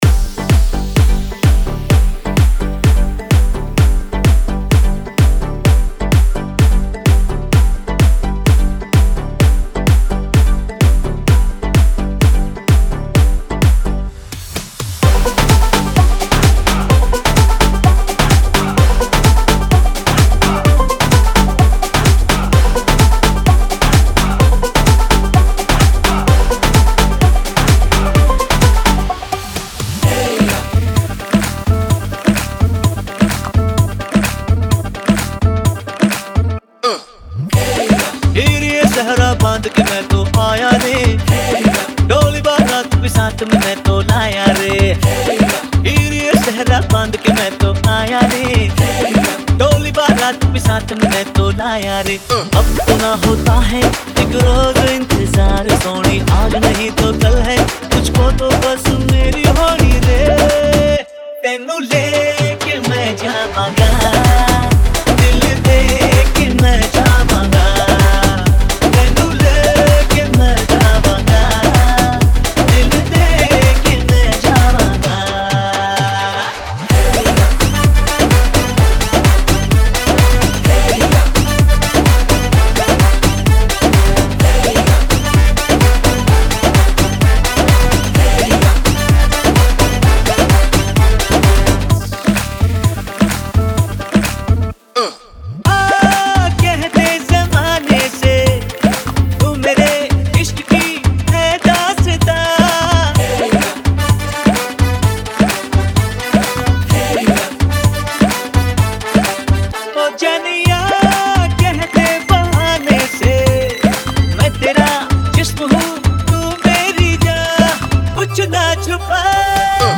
WEDDING REMIX